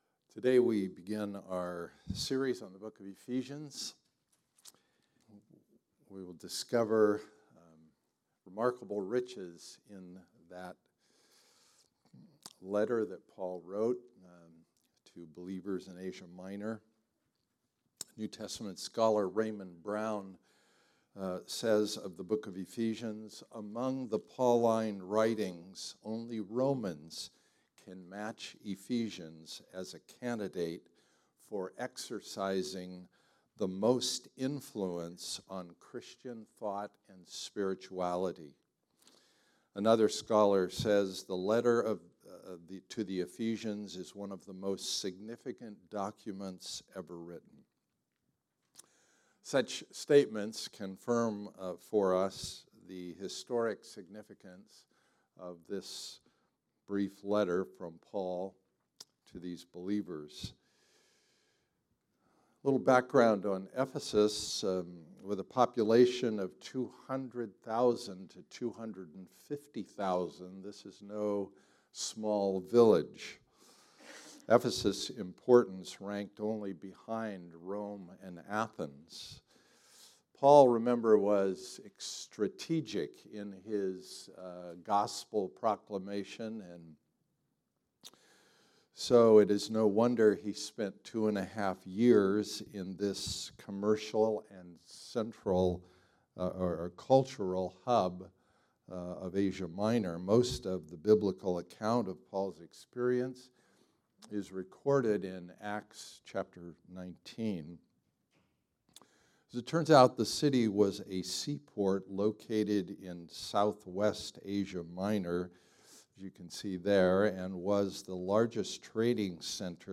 Type: Sermons